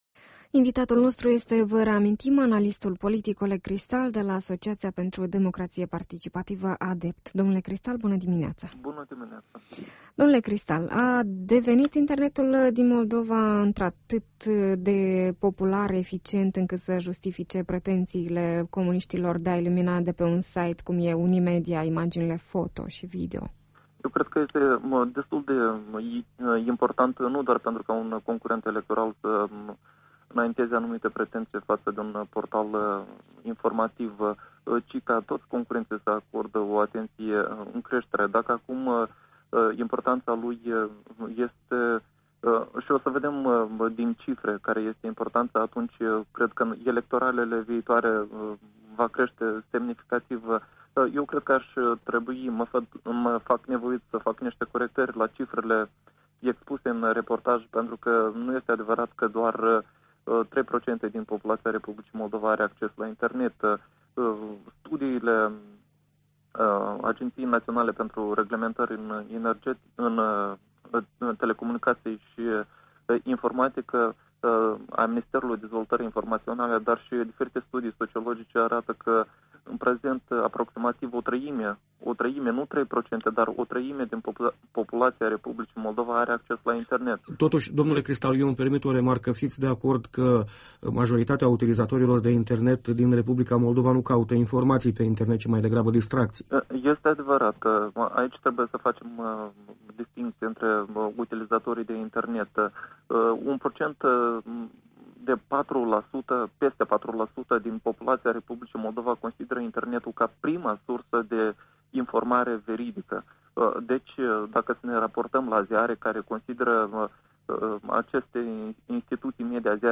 Despre rolul Internetului ca mijloc de informare în masă, despre eficienţa lui ca suport de propagandă politică şi campaniile electorale netradiţionale pe Internet, vom discuta azi cu analistul politic